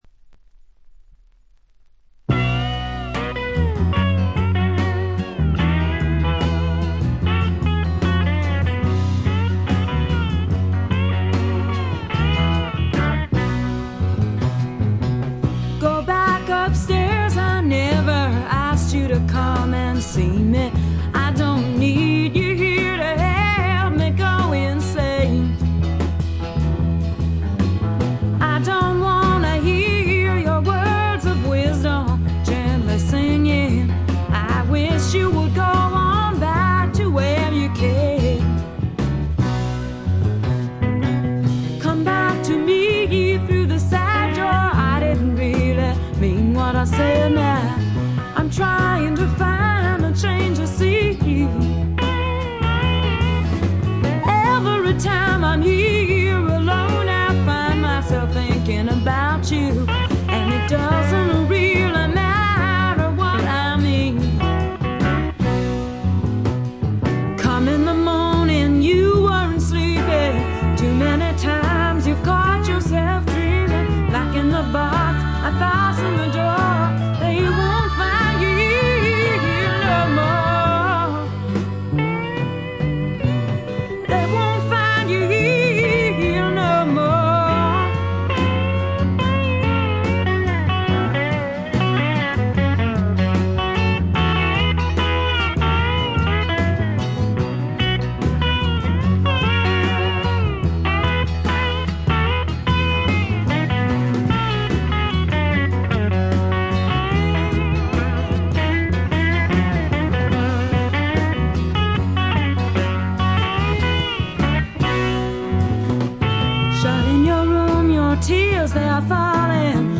少しコケティッシュで、ソウルフルで、実に魅力的なヴォイスの持ち主。女性スワンプの大傑作です。
試聴曲は現品からの取り込み音源です。